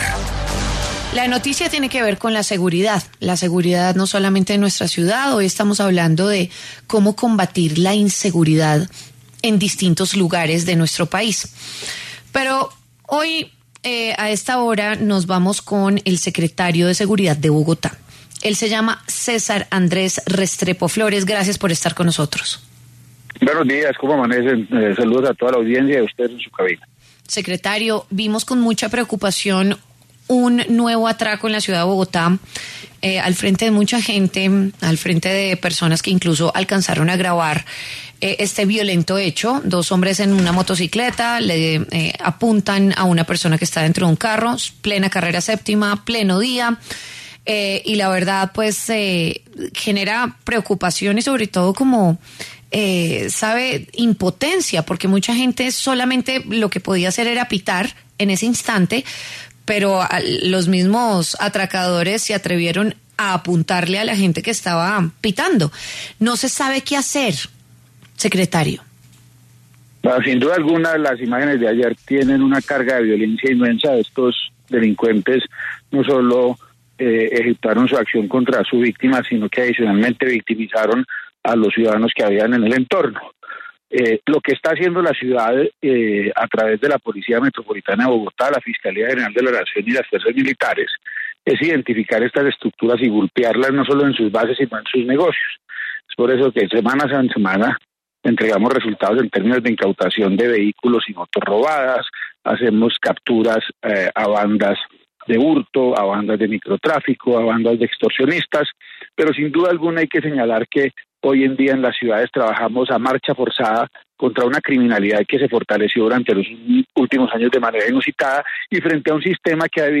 Sobre esto, el secretario de Seguridad de Bogotá, César Andrés Restrepo, habló en W Fin de Semana y aseguró que estos delincuentes no solo intimidaron a las víctimas sino también a los ciudadanos que estaban al rededor.